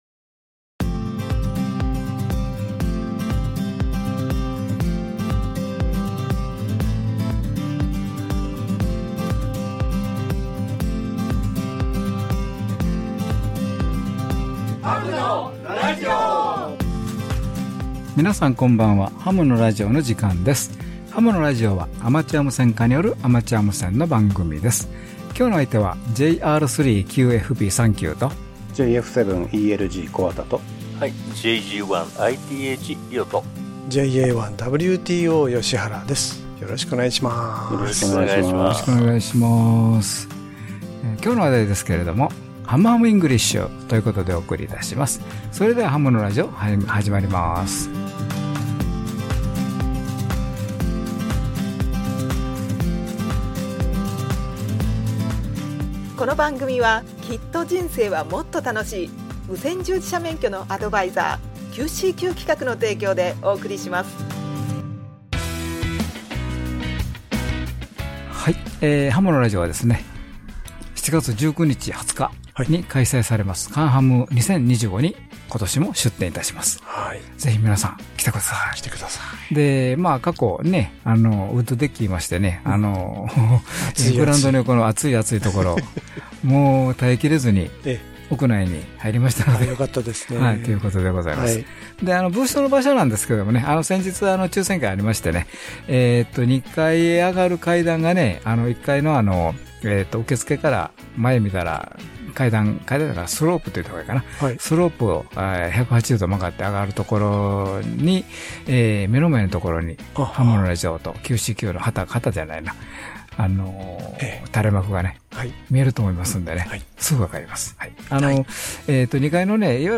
(2025/7/6 ラジオ成田から放送)